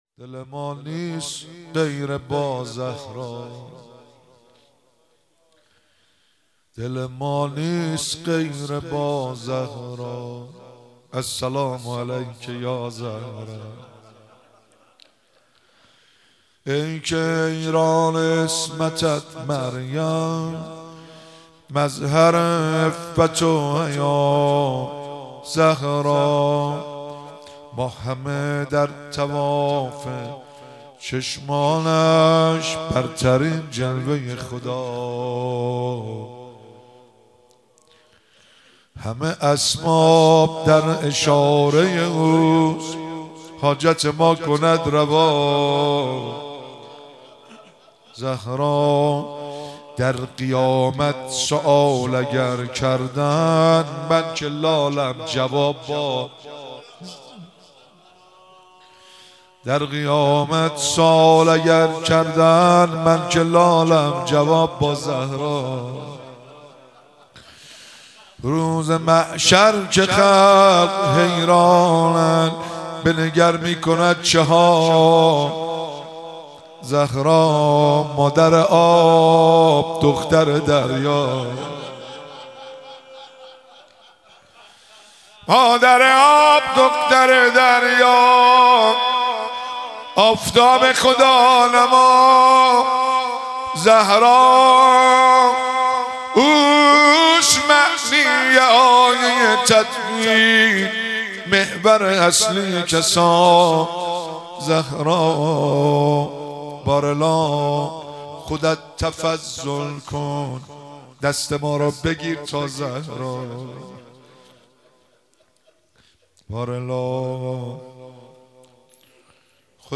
مراسم مناجات خوانی شب اول ماه رمضان 1444
مراسم مناجات خوانی شب اول ماه رمضان 1444 دانلود عنوان روضه- دل ما نیست غیرِ با زهرا، السلام علیکِ یازهرا تاریخ اجرا 1402-01-01 اجرا کننده مناسبت رمضان موضوع مناجات با خدا نوع صوت روضه مدت زمان صوت 00:06:59